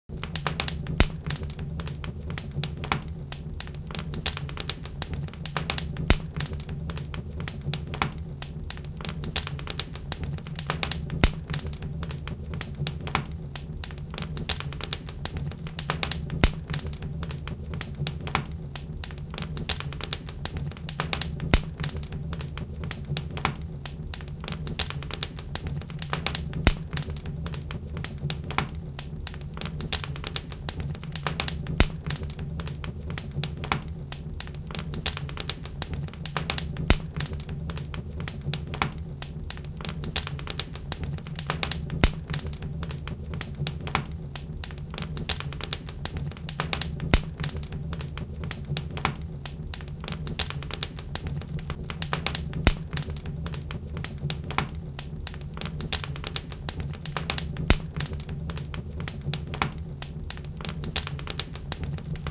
Пончик: (показывает на огонь - красная салфетка на леске поднимается всё выше по полотну с изображением лесной полянки, фоном (с усилением) слышен звук «Треск огня, костёр, пожар») <